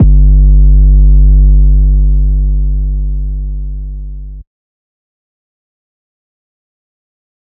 DMV3_808 6.wav